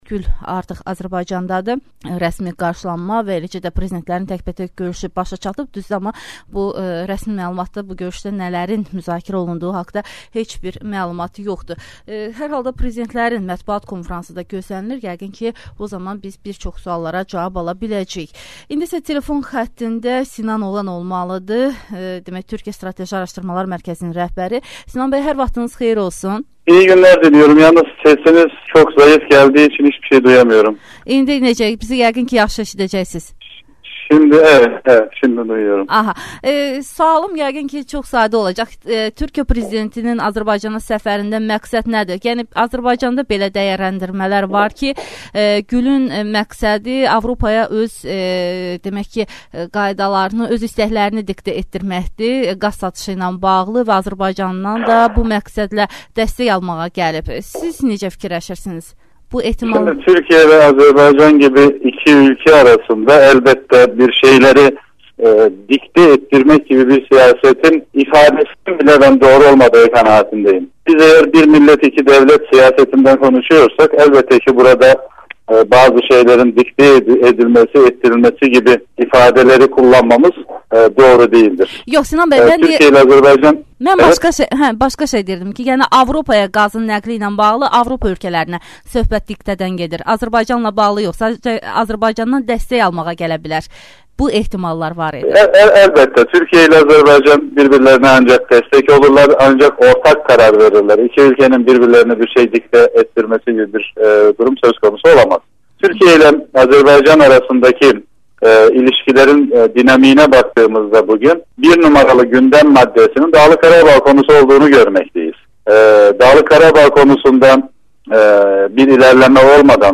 Bu sual «İşdən sonra»da səslənib. Müzakirələrdə politoloqlar Sinan Oğan və Vəfa Quluzadə iştirak ediblər